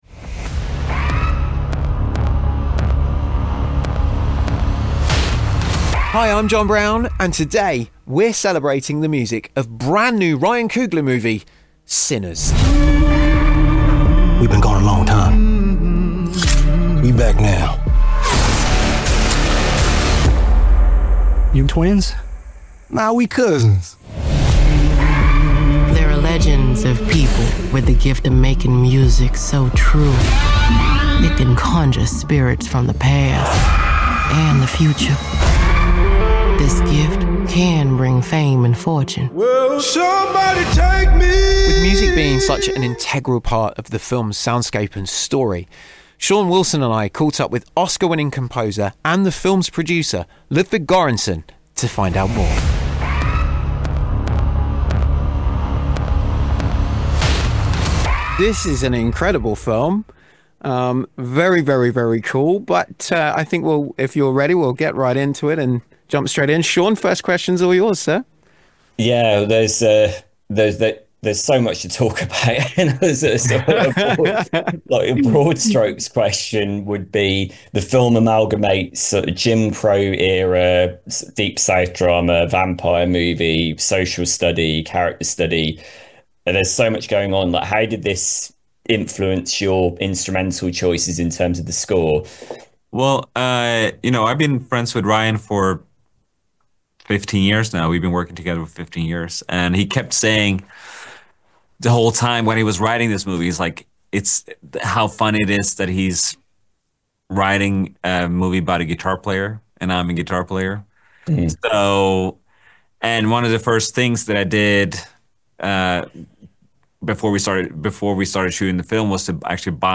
An honest and often funny insight into the week's new releases across Cinema, Streaming and Home Entertainment platforms, with Guest Interviews & more!